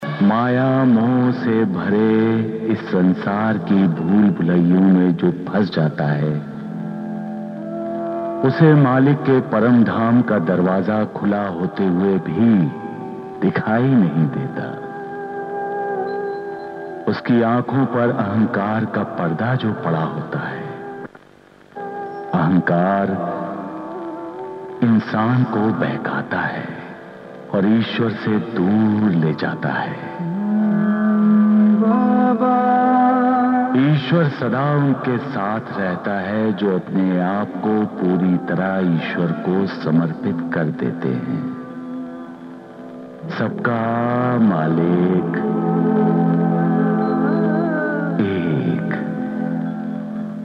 Some dialogues from the Ramanand Sagar's SAIBABA,